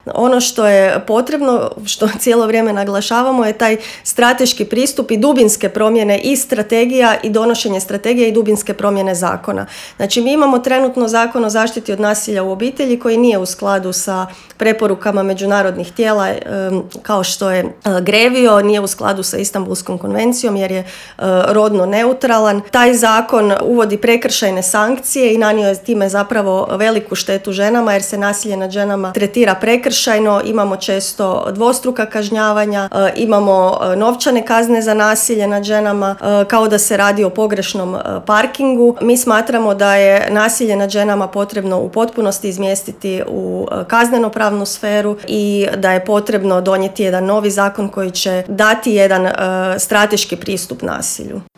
koju smo ugostili u intervjuu Media servisa